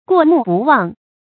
過目不忘 注音： ㄍㄨㄛˋ ㄇㄨˋ ㄅㄨˋ ㄨㄤˋ 讀音讀法： 意思解釋： 看過一遍就不會忘記；形容記憶力強；也形容給人的印象深刻。